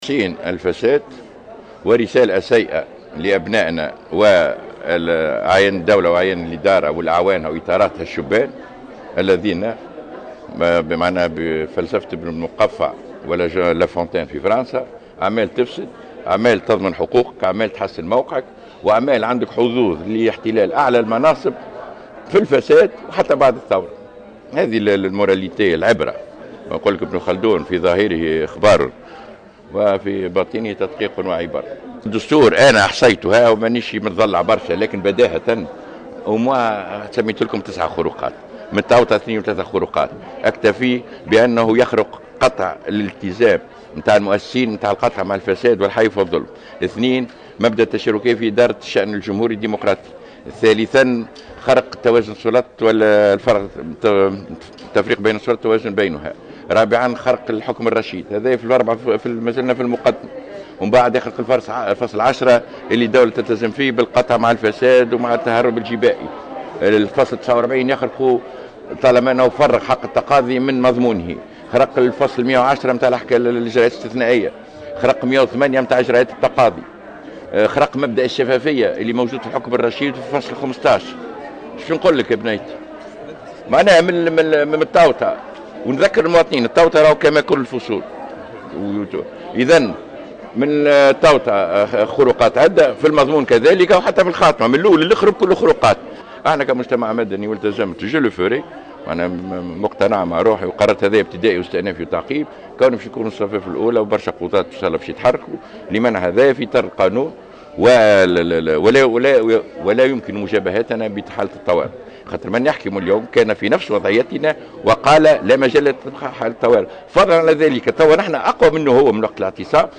Il a ajouté, en marge d’une conférence de presse tenue ce jeudi 27 août 2015, que cette loi comporte de nombreuses défaillances, et qu’elle s’oppose au contenu de la constitution tunisienne.